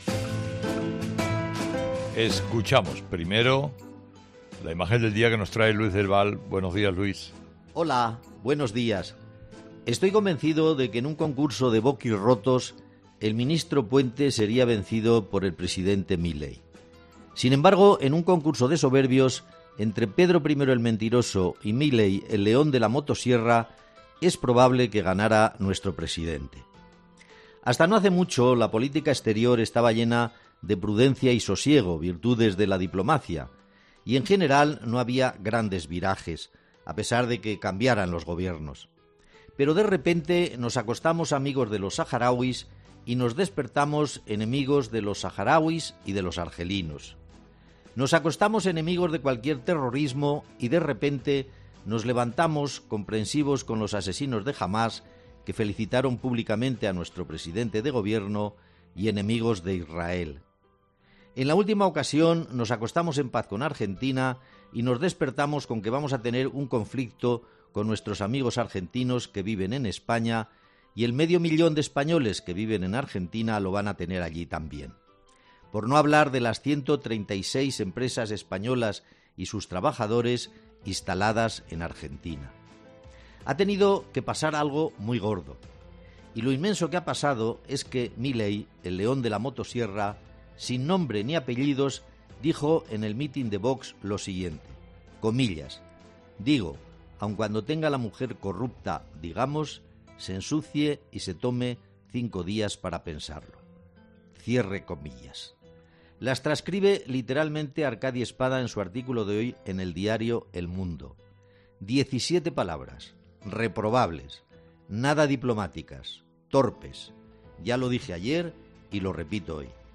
Habla Luis del Val en su 'Imagen del día' en 'Herrera en COPE' de las malas relaciones entre España y Argentina